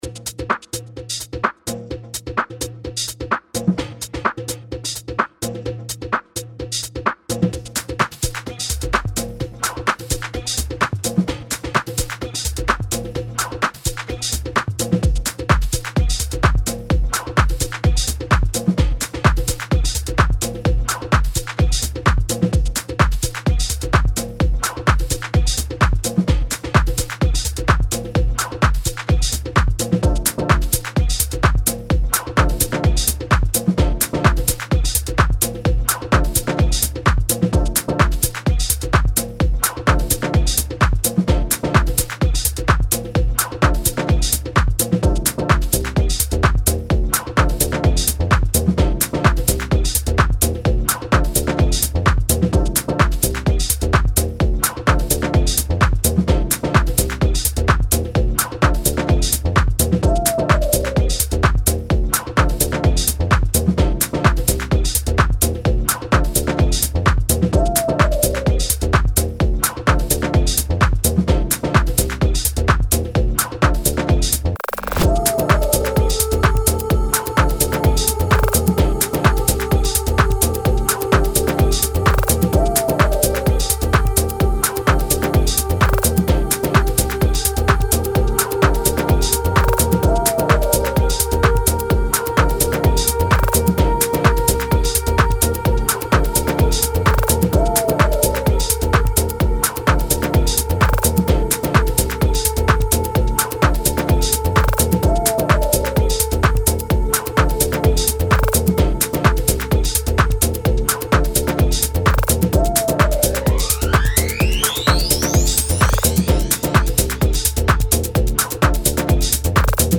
Genre House